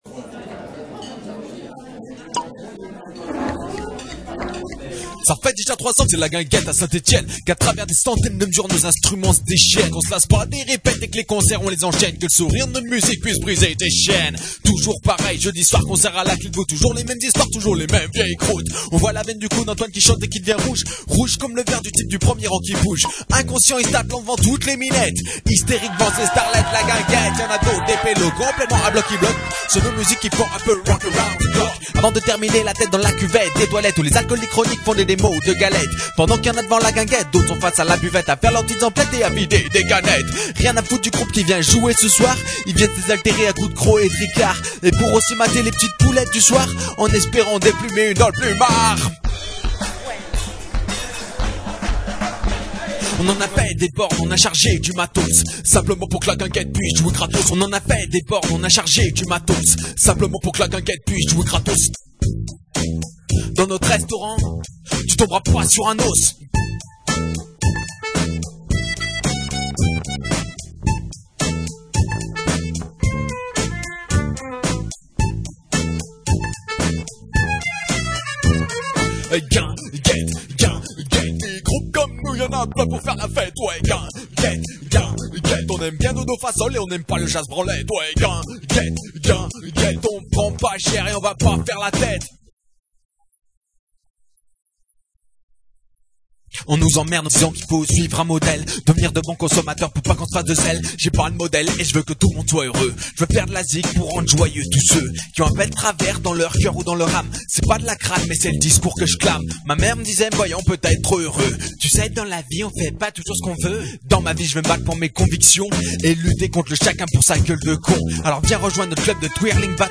Ce concert de musique actuelles s'est déroulé à la Clé d'voûte (7, rue Basse de Rives 42100) avec la participation de deux groupes de la région stéphanoise :
La guinguette à vapeur (chanson française)